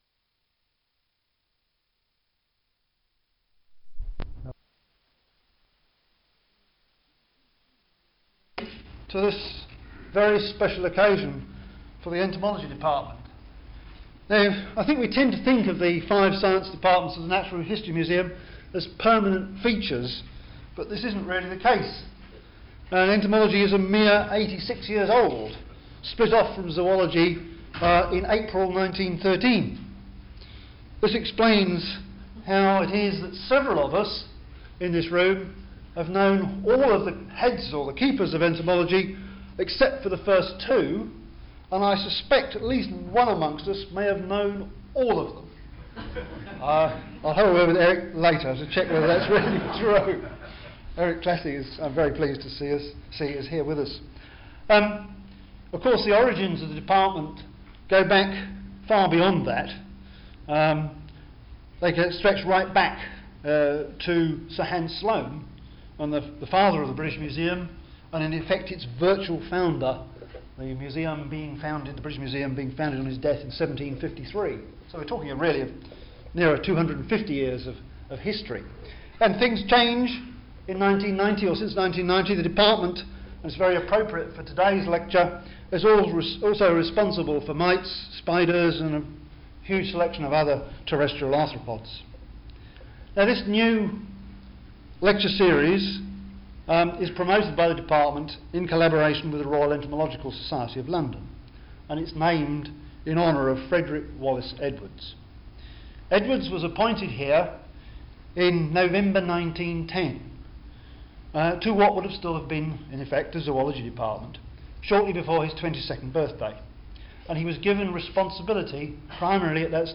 Frederick W. Edwards Annual Lectures